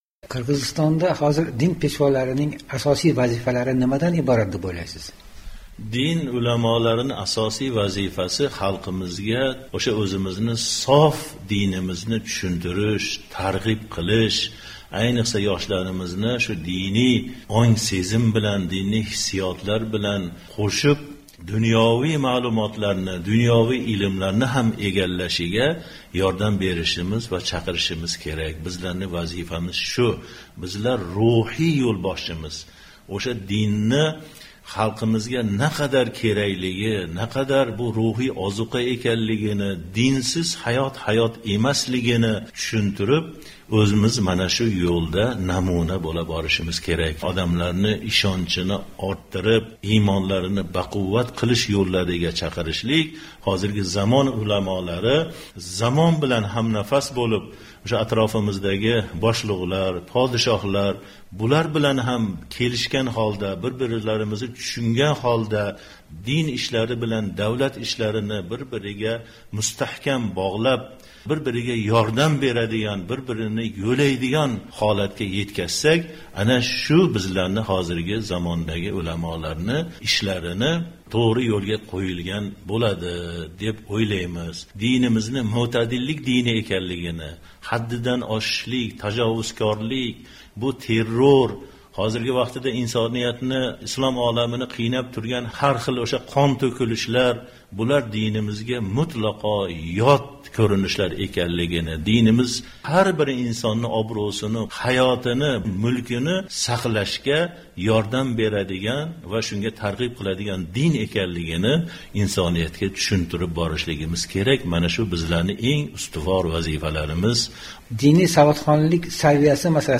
suhbat